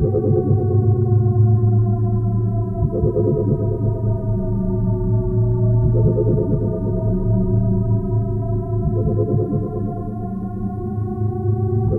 bridge3.ogg